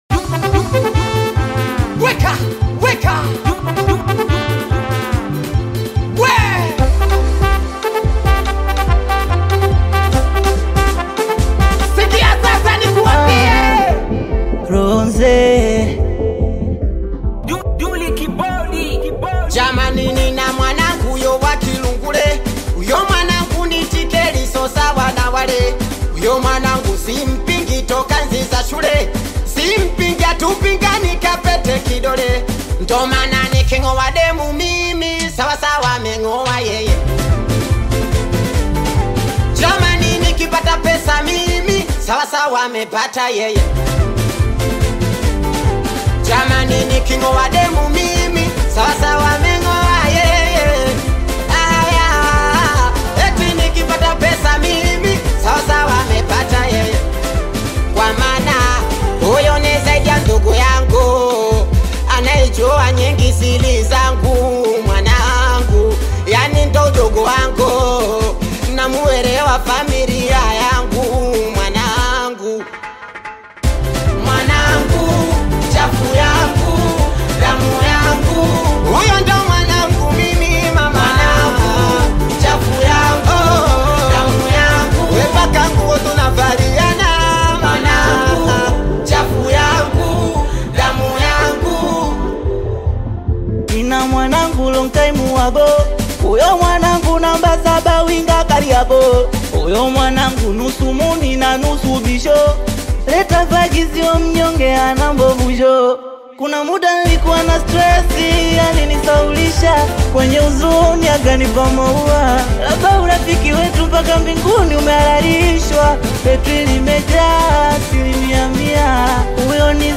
rap single